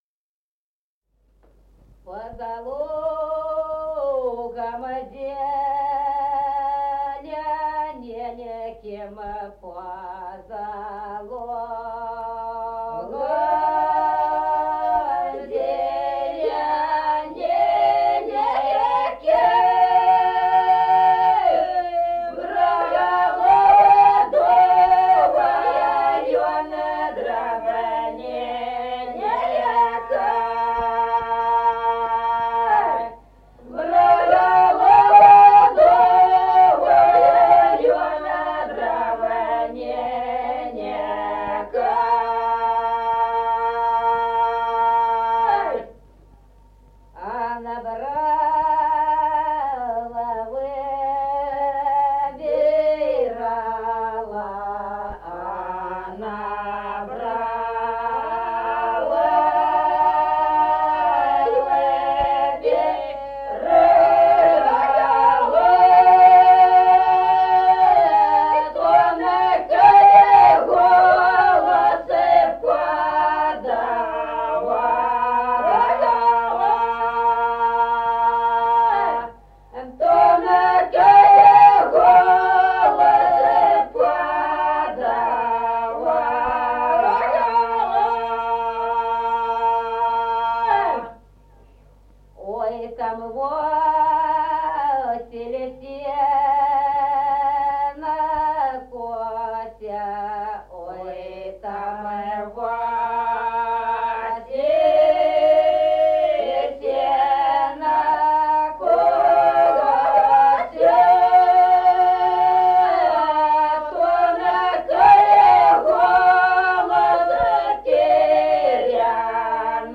Народные песни Стародубского района «По-за лугом зелененьким», лирическая.
1953 г., с. Остроглядово.